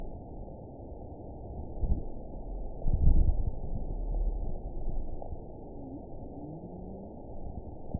event 919354 date 12/31/23 time 07:16:47 GMT (1 year, 11 months ago) score 6.54 location TSS-AB03 detected by nrw target species NRW annotations +NRW Spectrogram: Frequency (kHz) vs. Time (s) audio not available .wav